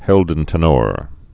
(hĕldən-tə-nôr)